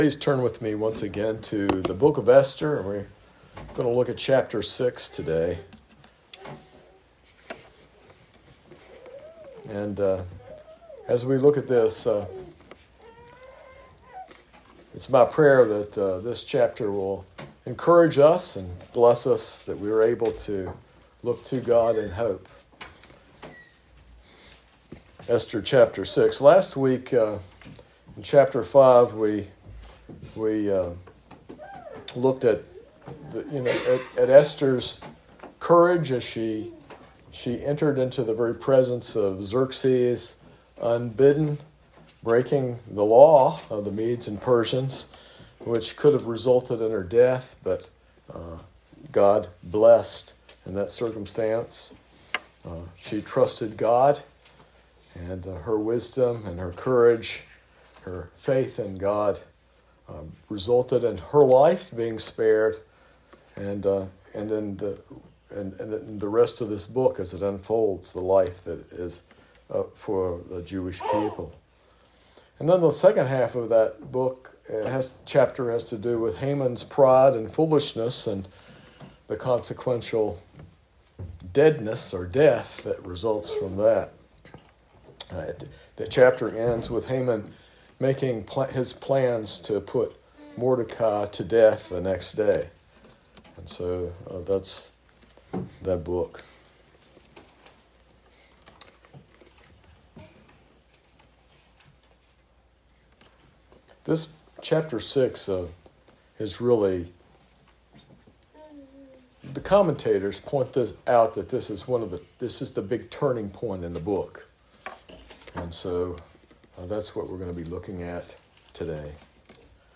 This sermon explores Esther chapter 6 as a pivotal moment, highlighting God’s sovereignty in turning sorrow into blessing. It emphasizes that while human sin brings suffering, God’s faithfulness and mercy ultimately deliver His people, culminating in the eternal turning point of salvation through Jesus Christ.